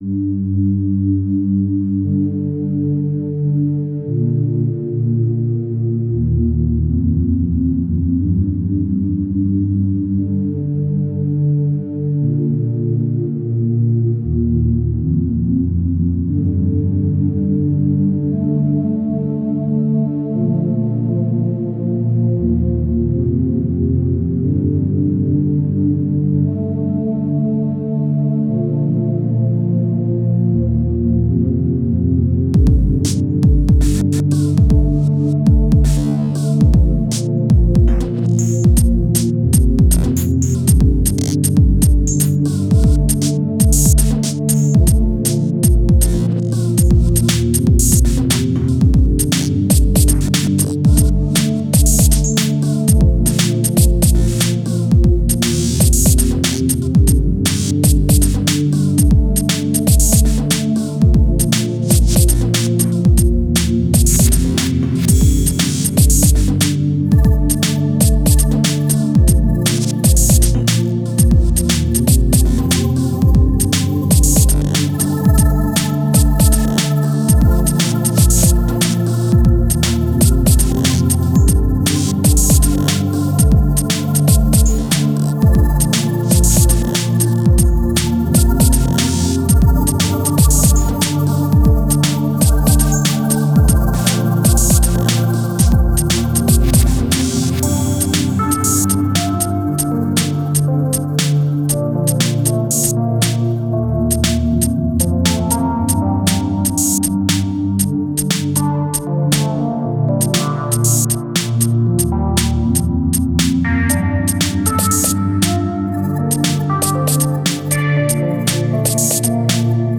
Genre: IDM, Ambient.